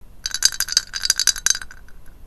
あご君土鈴 その1の音(mp3音声ファイル,3秒,57kバイト)